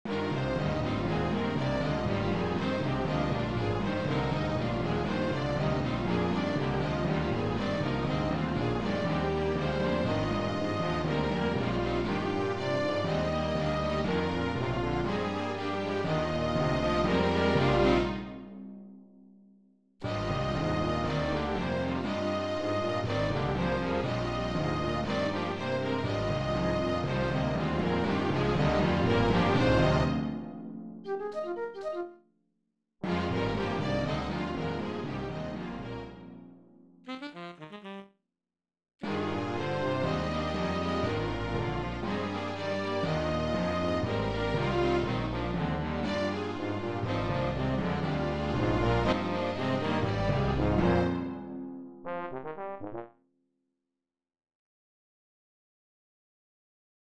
From my Third most recent original musical composition Symphony; Duisburg Somer.